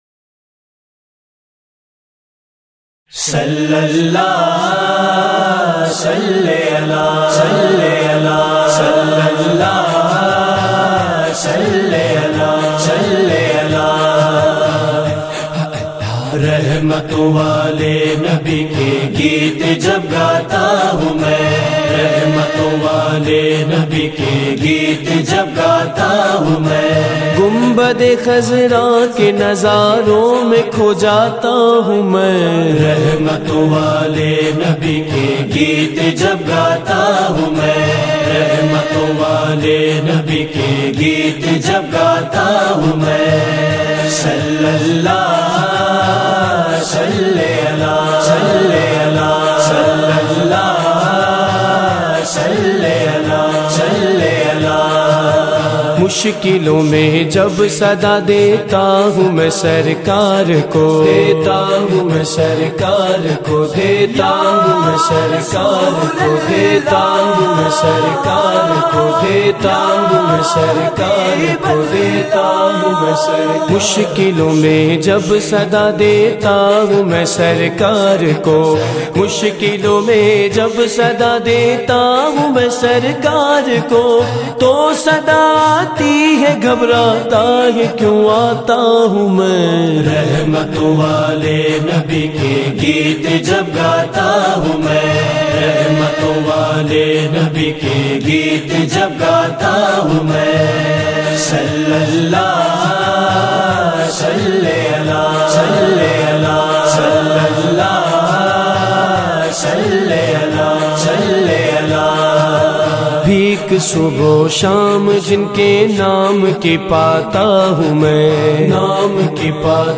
" Naat MP3